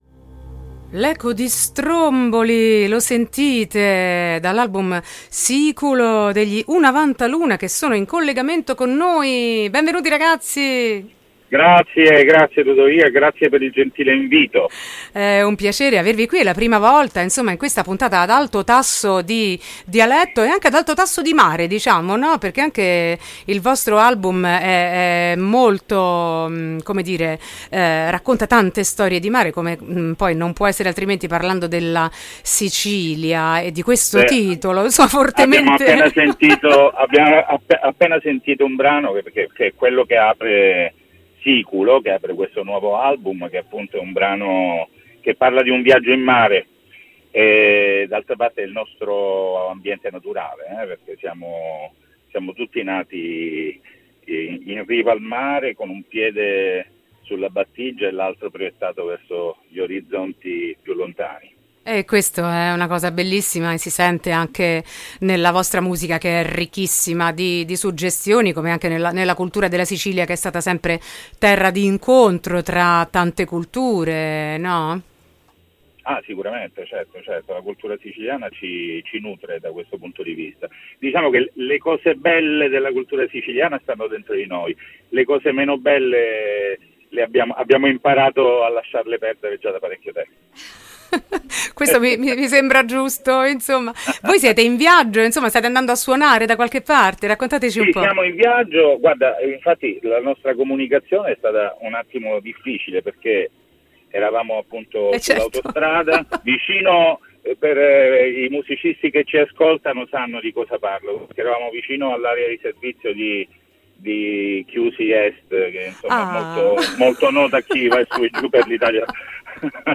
intervista-unavantaluna.mp3